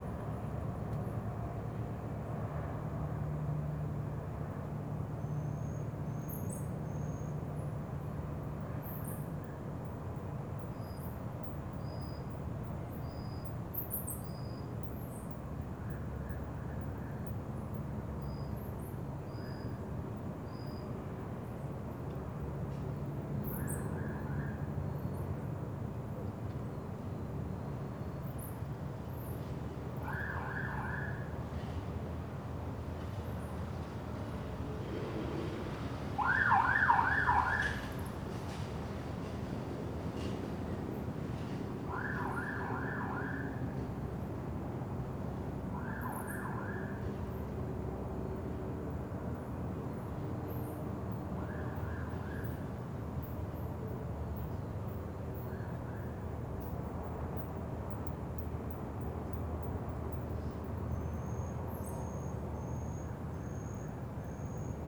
CSC-04-209-LE - Ambiencia residencial goiania noite sirene de policia passando, morcegos e aviao longe.wav